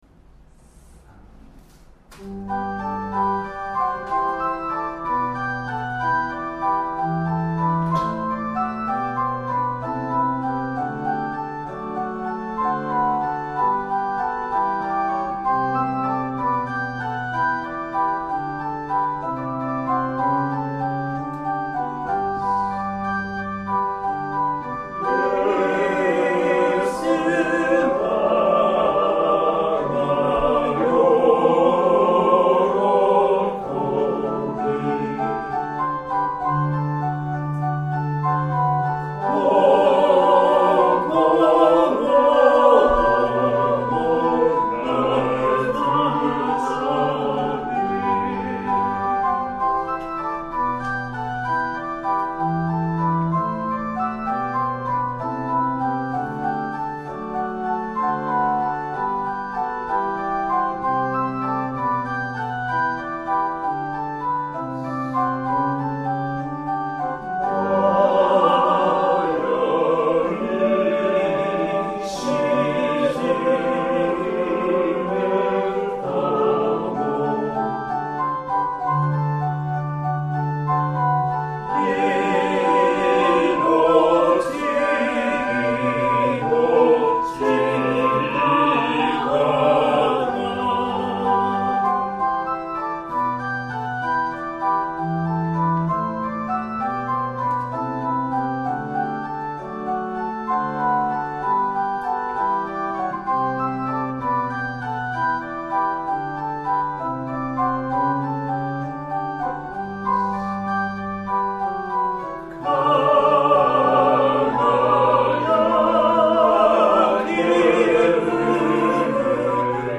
♪聖歌隊練習補助音源
Tonality = G　Pitch = 440　Temperament =Equal
1　 Organ そのまま none